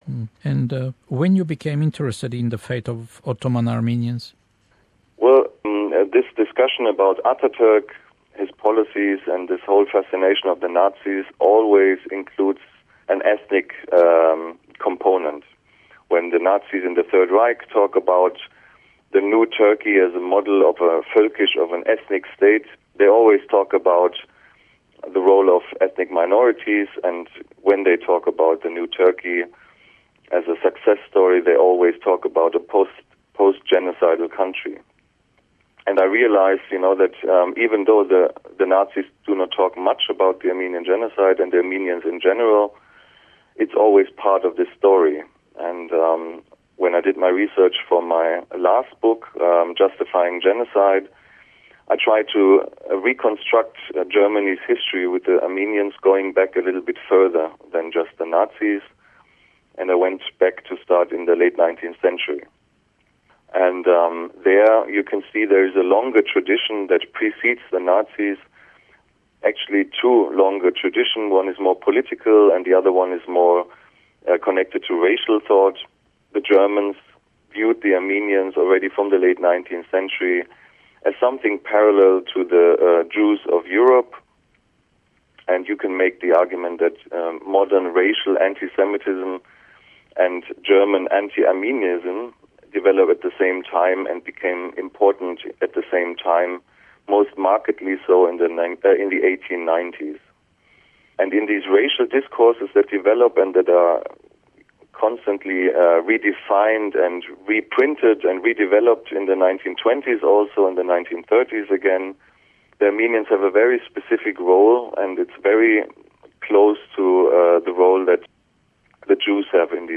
The subject of the second part of the interview is his book Justifying Genocide: Germany and the Armenians from Bismarck to Hitler. He explains the reasons successive German governments and certain circles in Germany viewed Armenians parallel to the Jews and justified the brutal treatment of Armenians by the hand of the Turks.